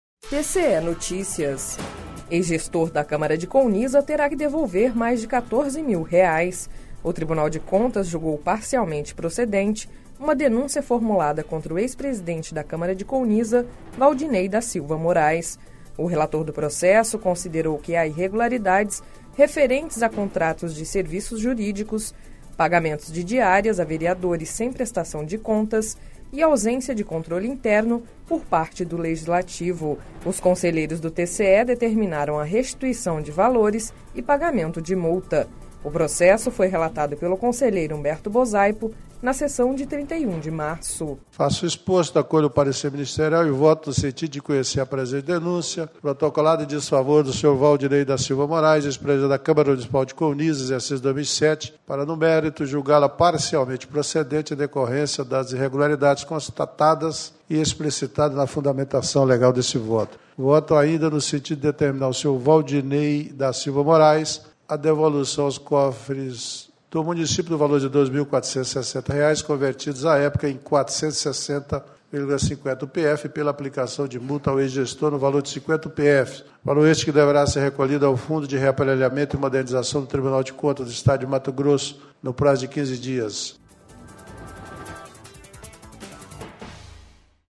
Sonora: Humberto Bosaipo – conselheiro do TCE-MT